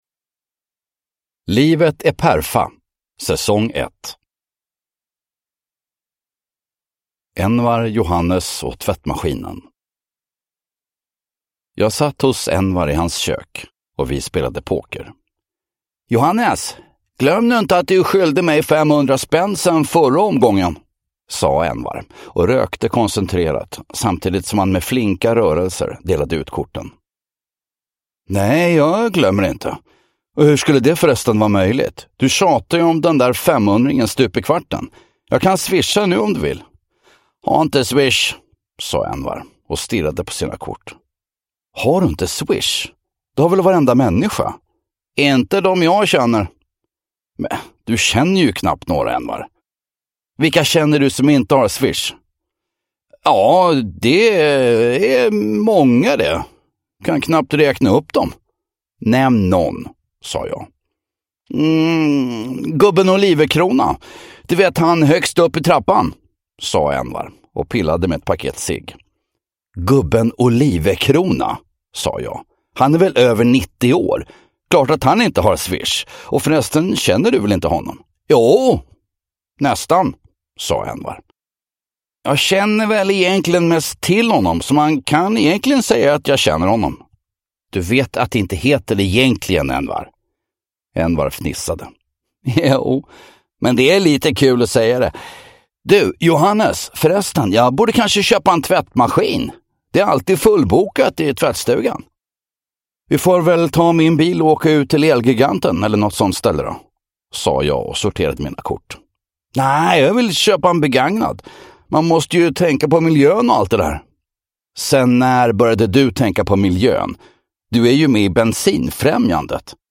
Envar, Johannes och tvättmaskinen (S1E1 Livet e perfa!) – Ljudbok
Feelgood Humor Njut av en bra bok
Säsong 1 av ”Livet e perfa!” består av tio fristående avsnitt, där Fredde Granberg är i högform i denna vilda sitcom i ljudboksformat.
Uppläsare: Fredde Granberg